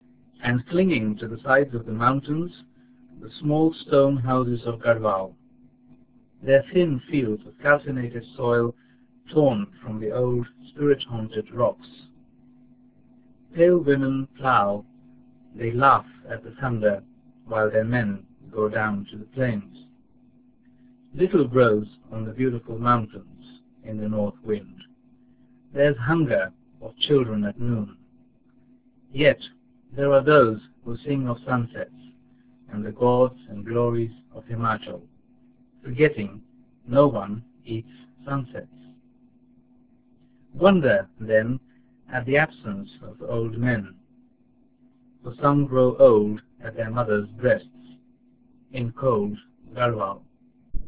Garhwal Himalaya - poem by Ruskin Bond.
poem001.ra